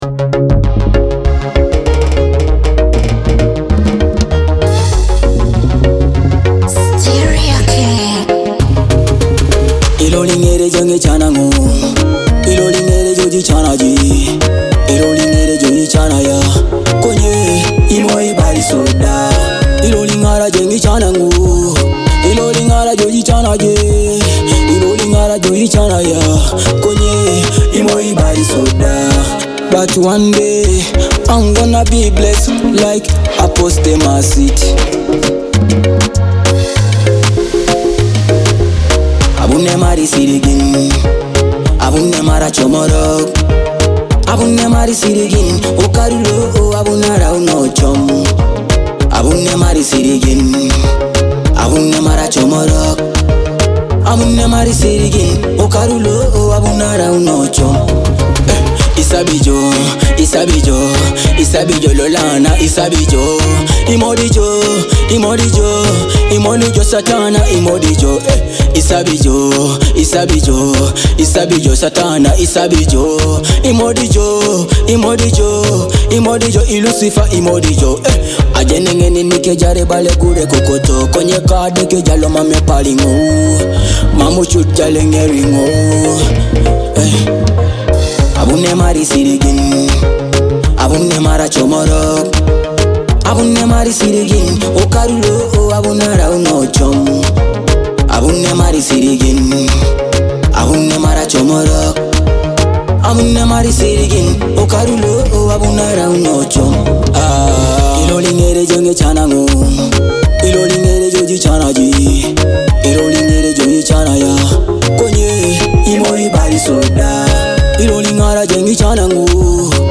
a high-energy fusion of Dancehall and Afrobeat.